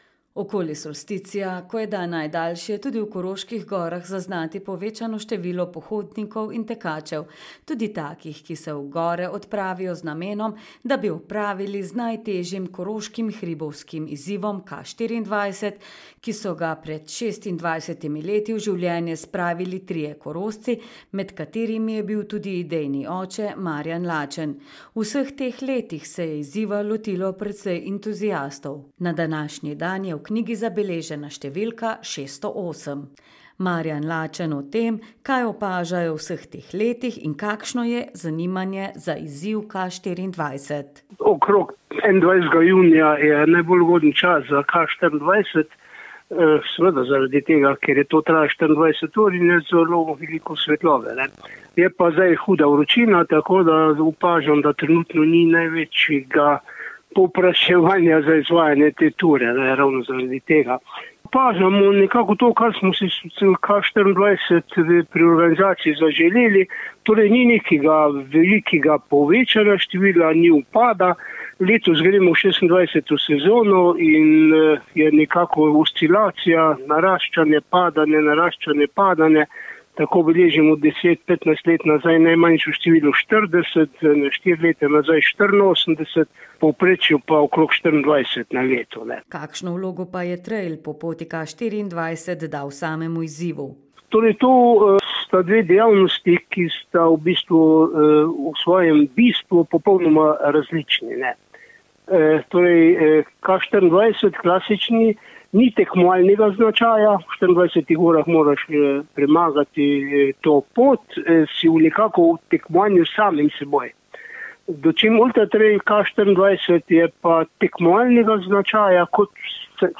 Lokalne novice | Koroški radio - ritem Koroške